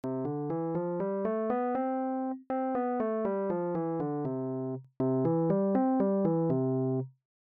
test-tone.mp3